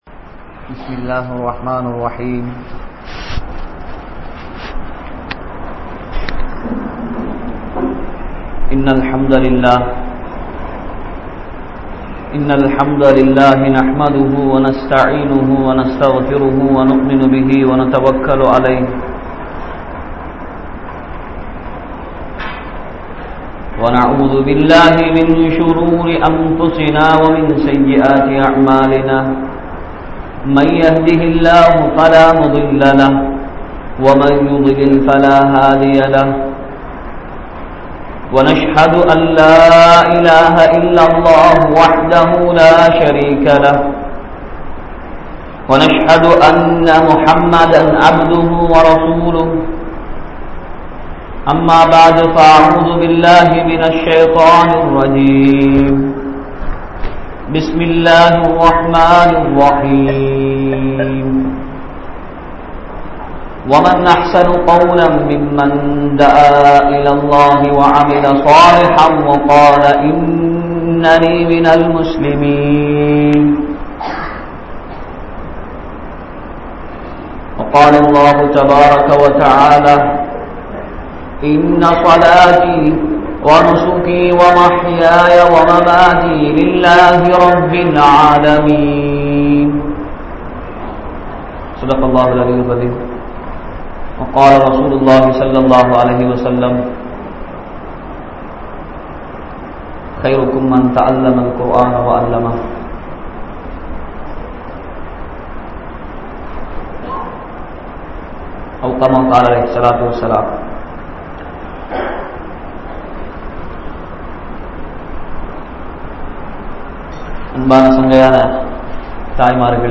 DUA`vin Perumathi (துஆவின் பெறுமதி) | Audio Bayans | All Ceylon Muslim Youth Community | Addalaichenai